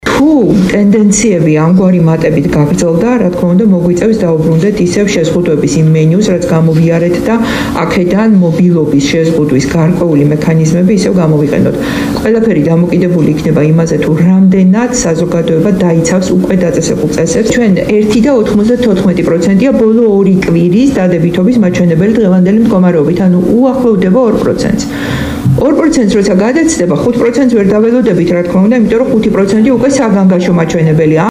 მოისმინეთ, თამარ გაბუნიას კომენტარი